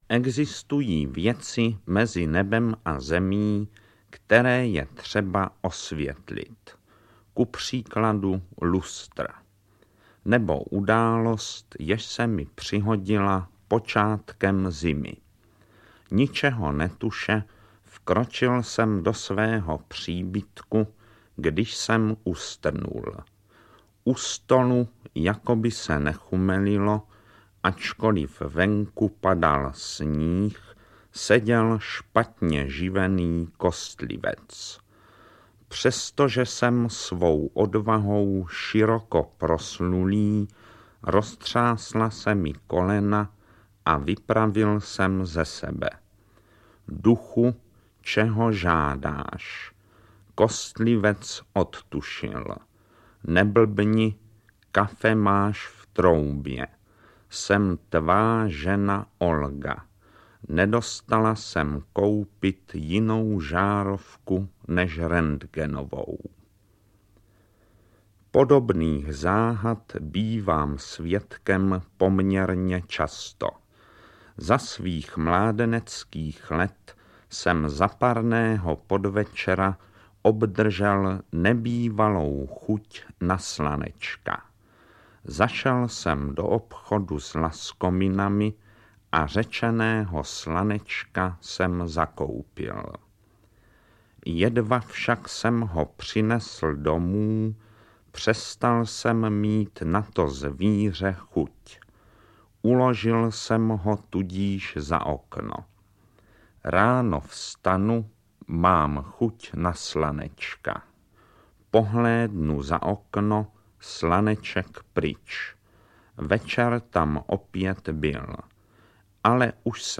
Dříve narození znají Jaroslava Válka jako Smutného muže, který právě svým smutkem, svým monotónním, huhňavým hlasem rozesmával své posluchače a diváky.
AudioKniha ke stažení, 1 x mp3, délka 28 min., velikost 25,6 MB, česky